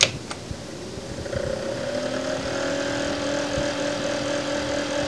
fan.wav